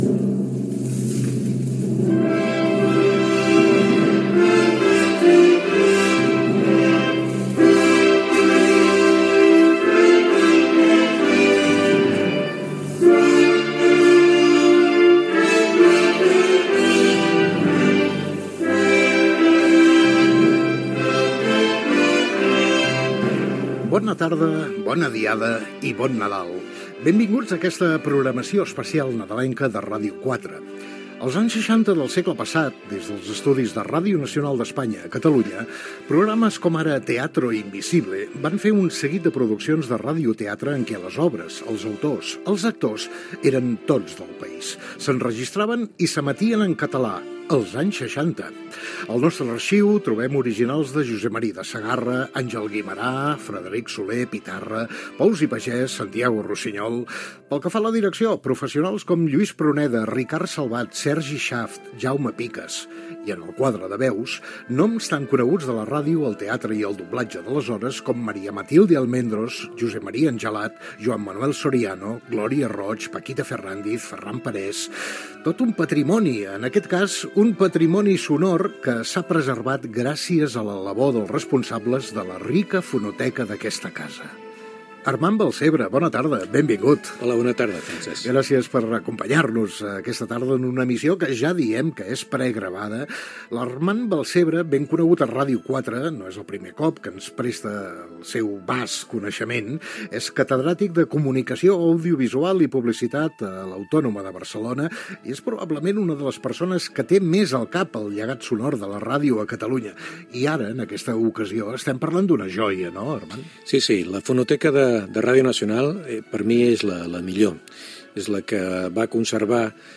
Sintonia